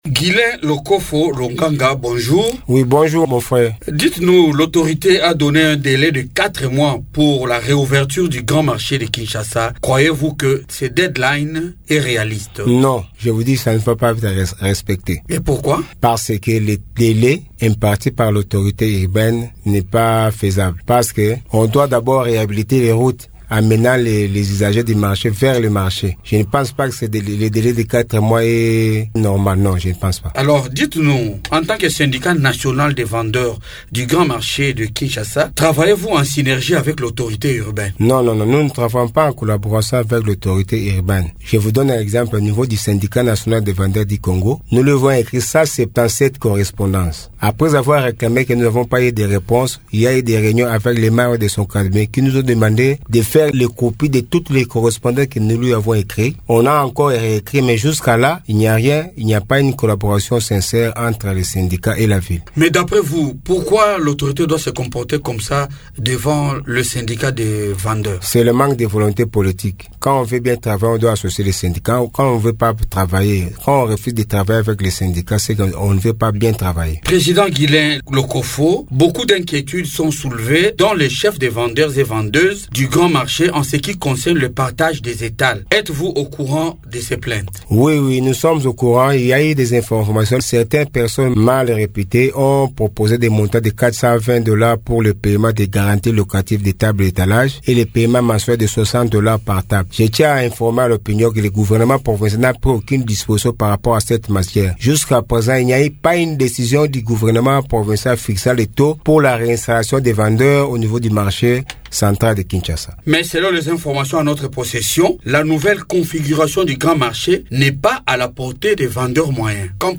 s’entretien